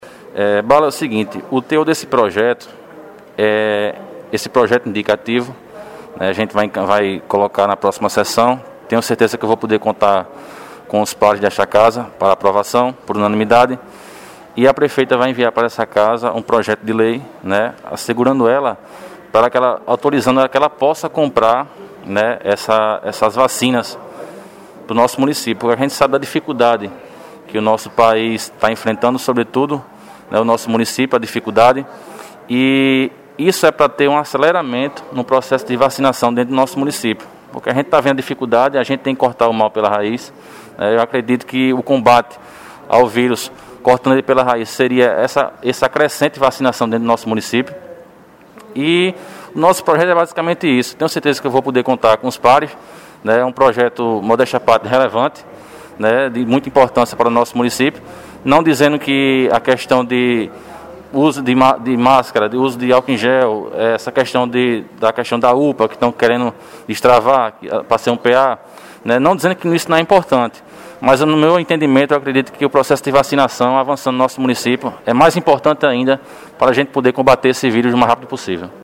O vereador Felipe Pessoa (Cidadania) deve apresentar nos próximos dias na Câmara Municipal de Rio Tinto, um projeto de lei que autoriza o município adquirir vacinas para o enfrentamento da pandemia do novo coronavírus (Covid-19). Ao blog, o parlamentar falou a respeito.